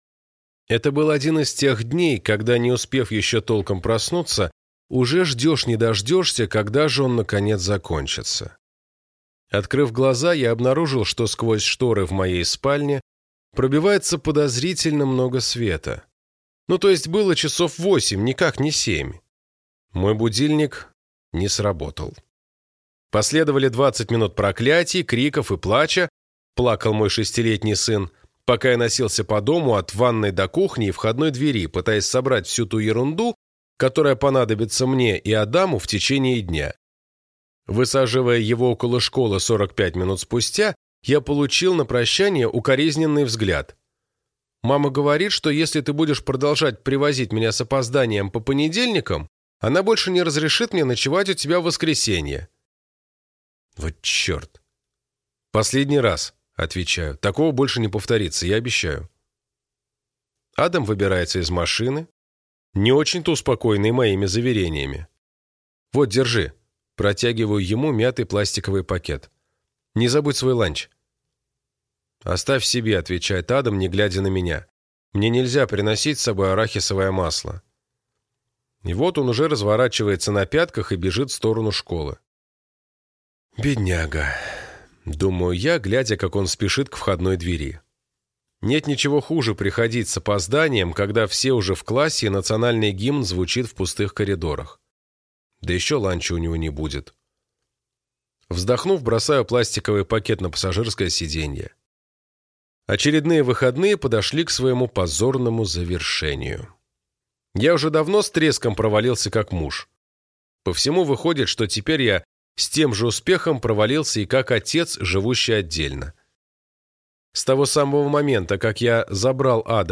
Аудиокнига 9 тайных посланий от монаха, который продал свой «феррари» | Библиотека аудиокниг